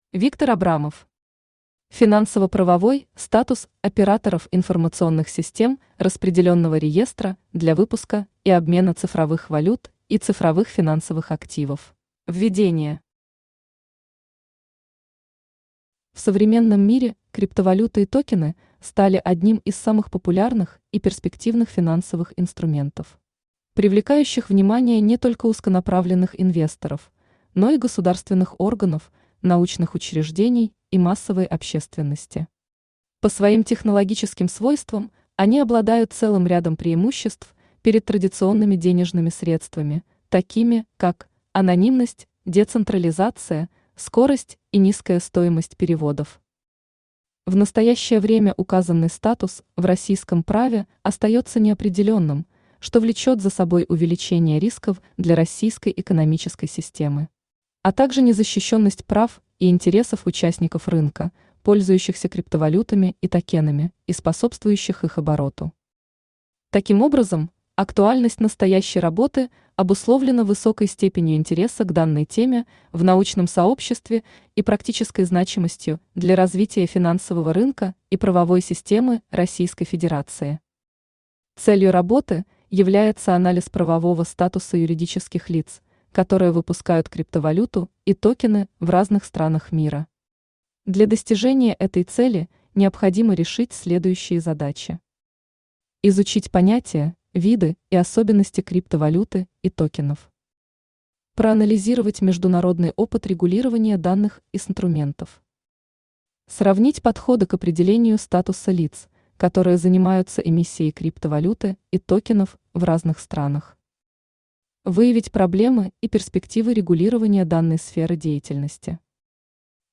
Аудиокнига Финансово-правовой статус операторов информационных систем распределённого реестра для выпуска и обмена цифровых валют и цифровых финансовых активов | Библиотека аудиокниг
Aудиокнига Финансово-правовой статус операторов информационных систем распределённого реестра для выпуска и обмена цифровых валют и цифровых финансовых активов Автор Виктор Абрамов Читает аудиокнигу Авточтец ЛитРес.